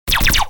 Lasers.wav